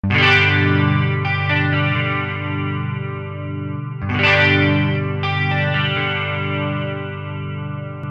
拍打吉他G大调
描述：清晰的G大调吉他开放和弦与合唱
Tag: 120 bpm Blues Loops Guitar Electric Loops 1.50 MB wav Key : Unknown